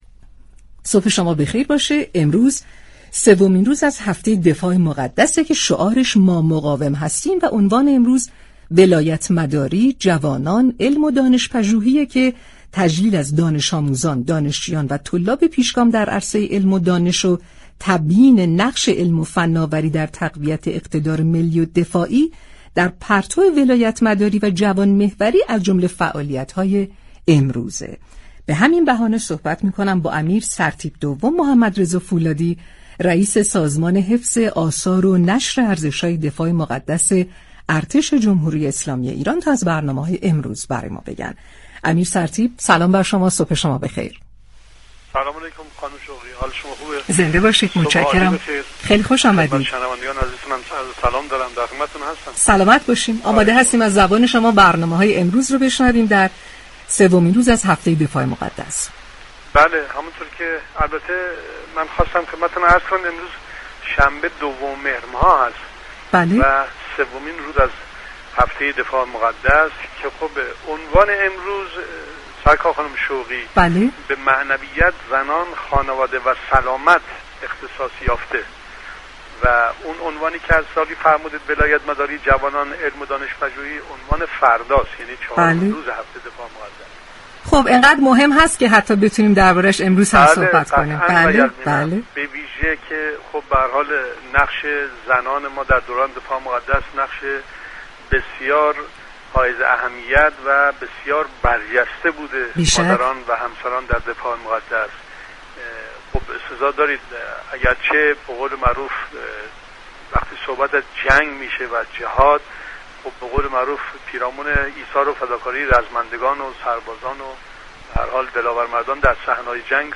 به گزارش پایگاه اطلاع رسانی رادیو تهران، امیر سرتیپ دوم محمدرضا فولادی رئیس سازمان حفظ آثار و نشر ارزش‌های دفاع مقدس ارتش جمهوری اسلامی ایران در سومین روز از هفته دفاع مقدس در گفت‌وگو با شهر آفتاب رادیو تهران گفت: عنوان سومین روز از هفته دفاع مقدس به معنویت،زنان، خانواده و سلامت اختصاص دارد.